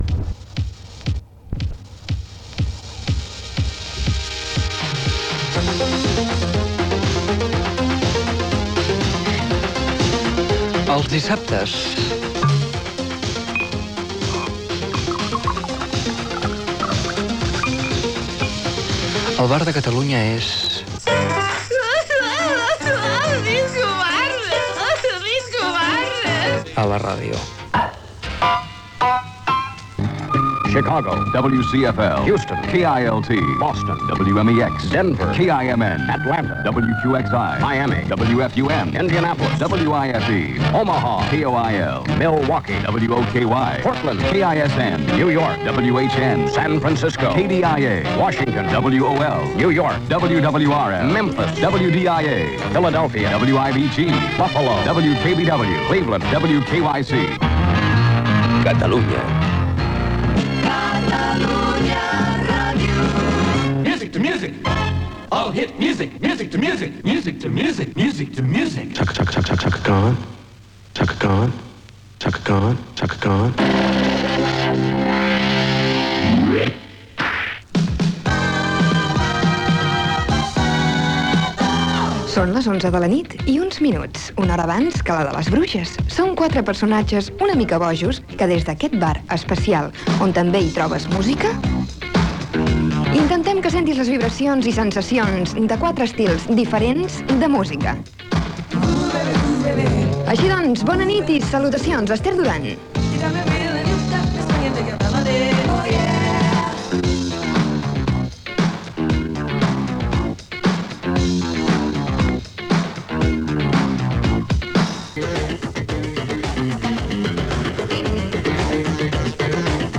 presentació, tema musical, indicatiu, tema musical
Musical
FM